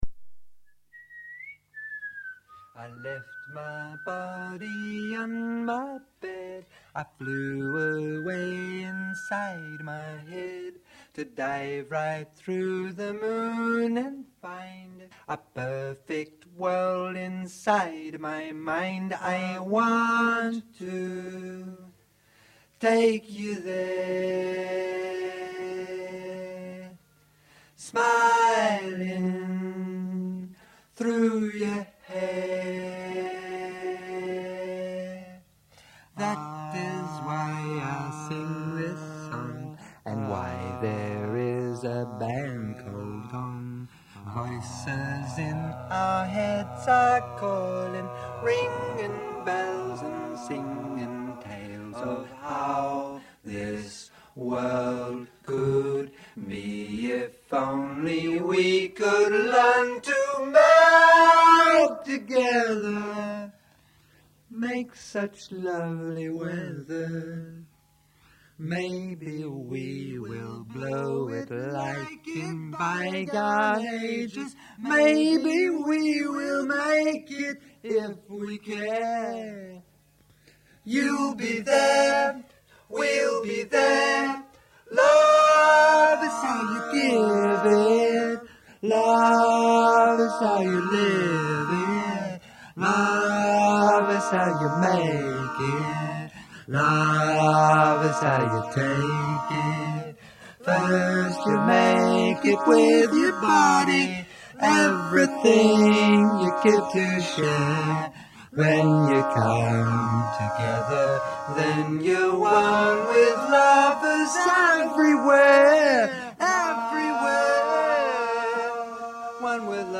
singer, song-writer and guitar player.